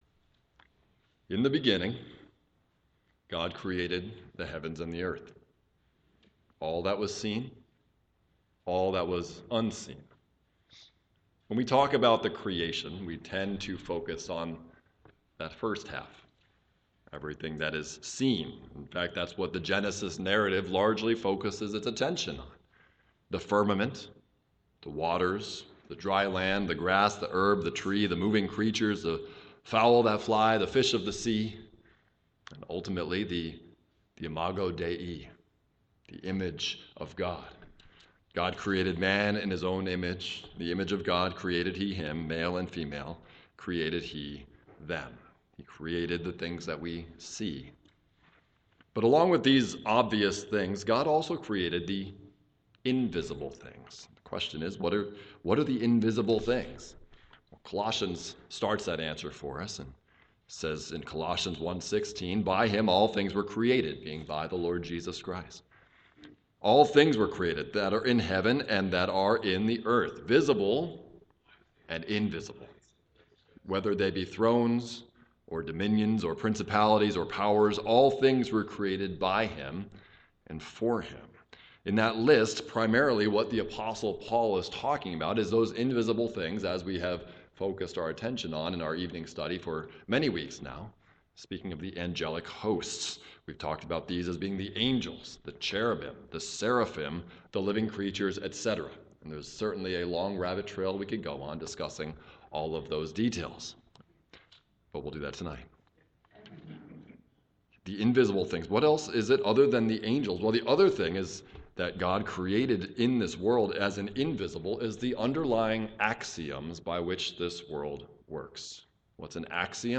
Luke: Sermon on Discipleship – On Blind Guides and Bad Fruit - Waynesboro Bible Church
Morning Worship